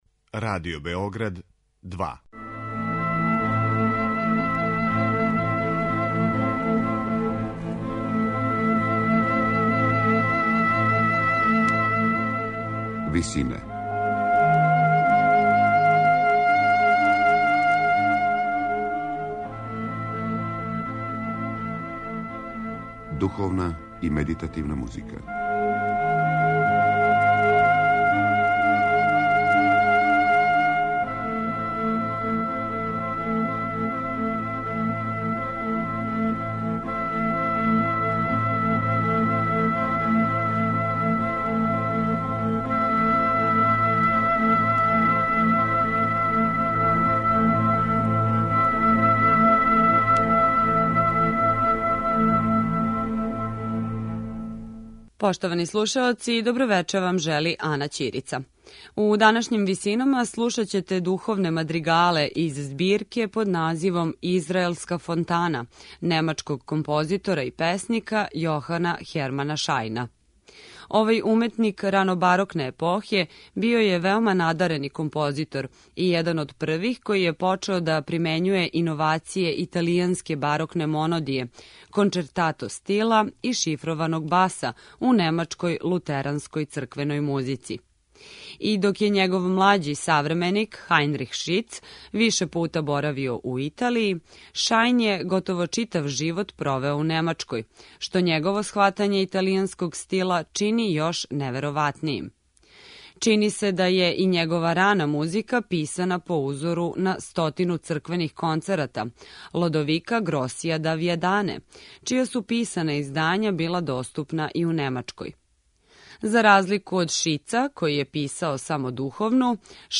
Духовне композиције
У данашњој емисији слушаћете духовне мадригале из збирке под називом "Израелска фонтана", немачког композитора и песника Јохана Хермана Шајна.